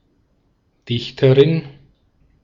Ääntäminen
Ääntäminen Tuntematon aksentti: IPA: /ˈdɪçtɐʁɪn/ Haettu sana löytyi näillä lähdekielillä: saksa Käännös Ääninäyte 1. poétesse {f} 2. poète {m} France Artikkeli: die .